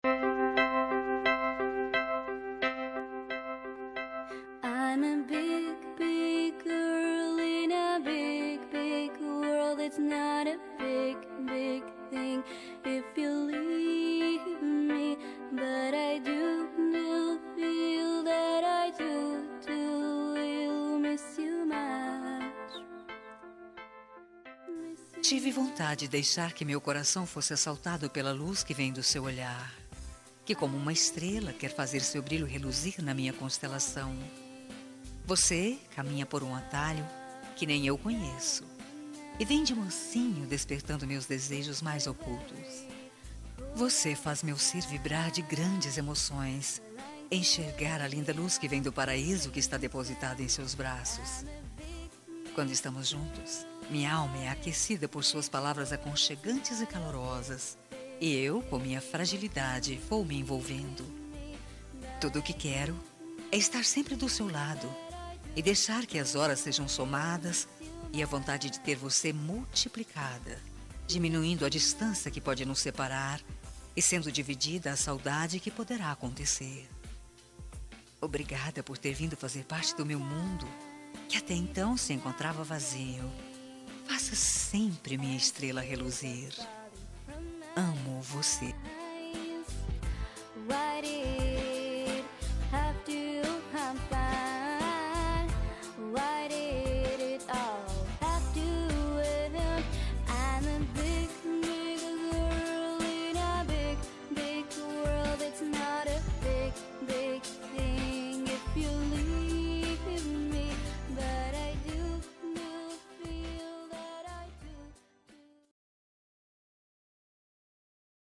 Telemensagem Romântica – Voz Feminina – Cód: 7855